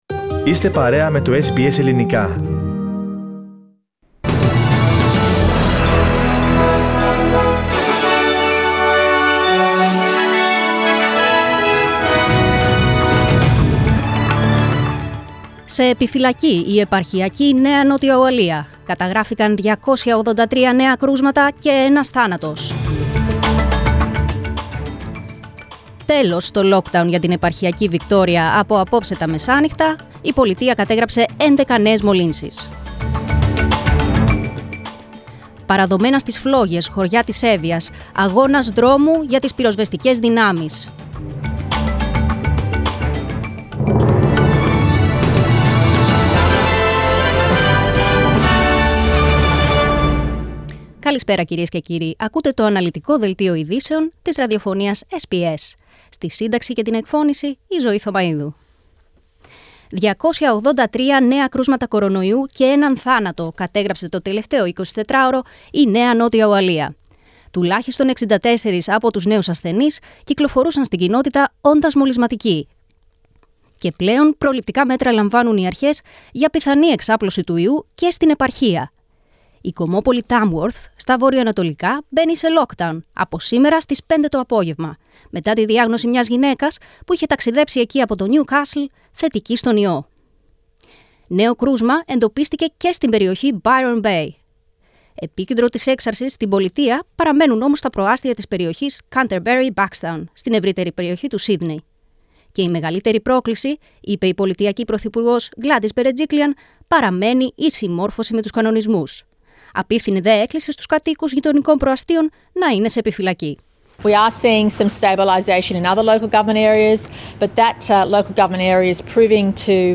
Δελτίο Ειδήσεων: Δευτέρα 9.8.2021